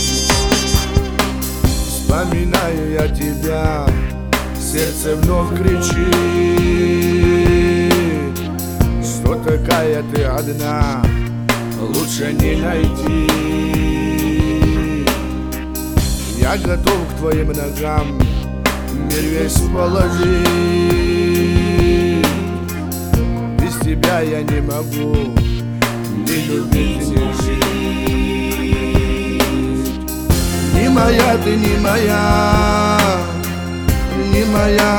Жанр: Музыка мира / Русские